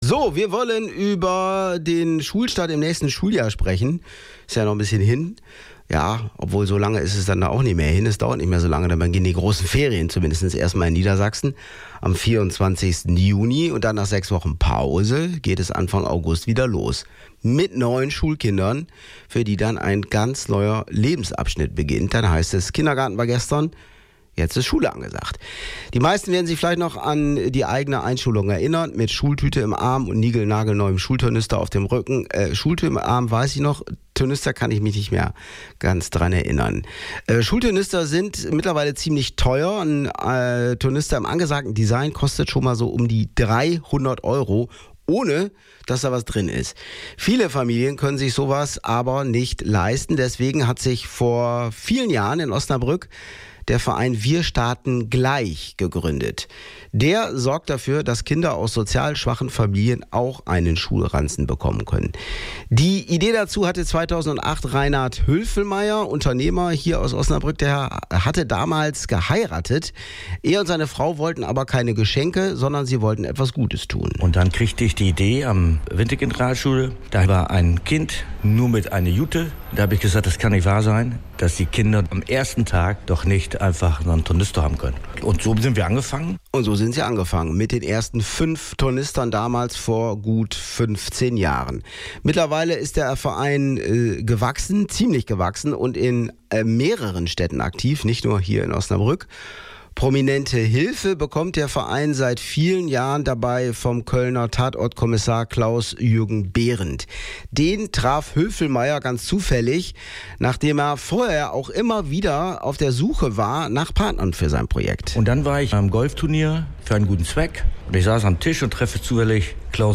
Bei der Packaktion im Rosenhof hat auch Oberbürgermeisterin Katharina Pötter fleißig mitgeholfen.